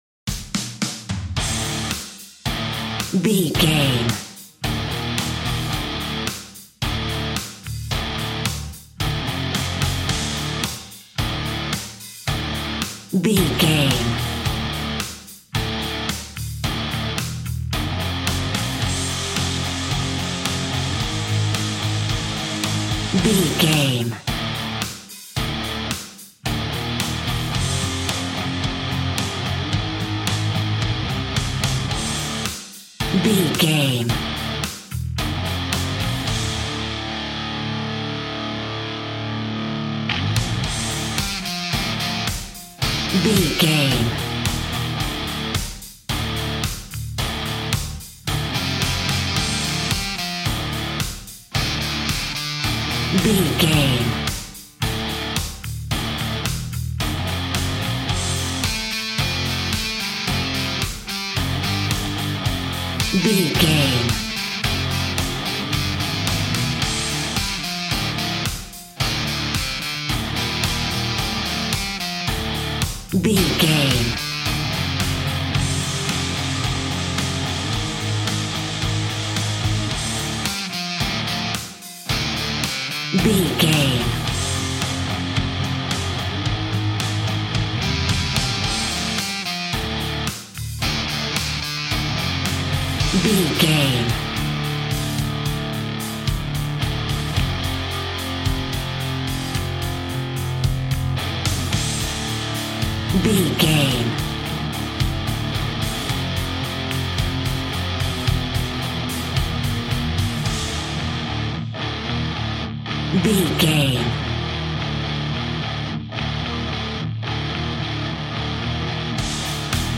Epic / Action
Fast paced
Aeolian/Minor
hard rock
instrumentals
Rock Bass
heavy drums
distorted guitars
hammond organ